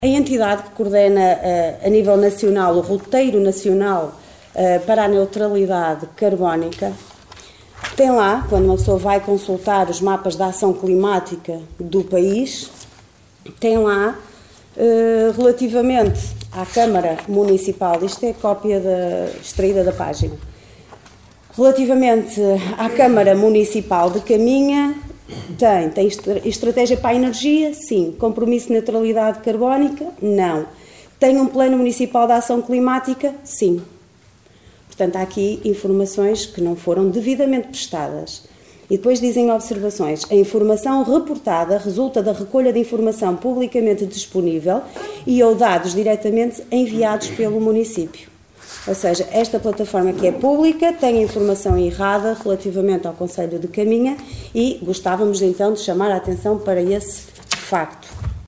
reuniao-camara-6-mar-planos-liliana-silva-002.mp3